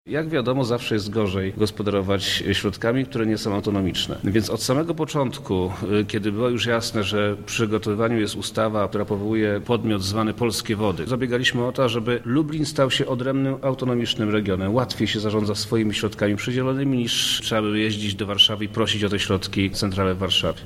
Jak mówi wojewoda lubelski, Przemysław Czarnek, ulokowanie siedziby w naszym regionie ma zapewnić autonomię działania i swobodę planowania wydatków.